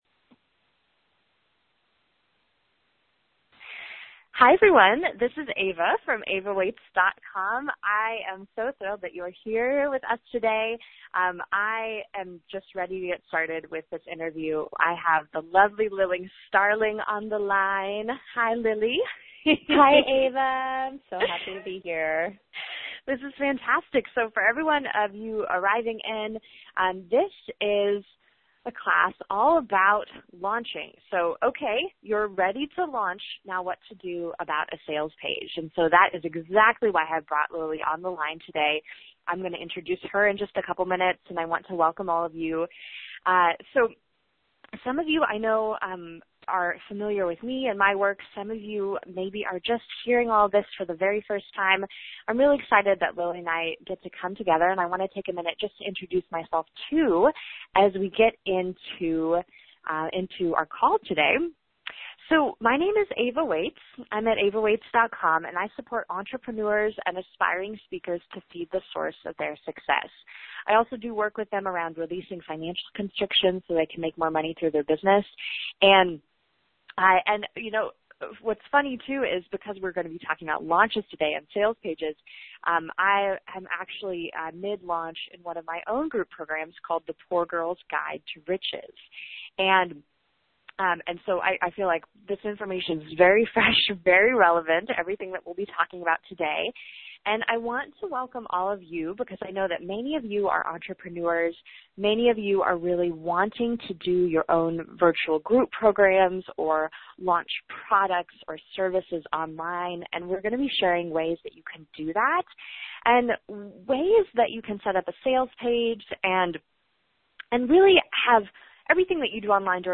Thursday, March 6th at 11am Pacific/2pm Eastern There’s more to launching a successful product or program than setting up a sales page and shopping cart. In this interview